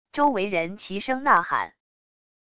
周围人齐声呐喊wav音频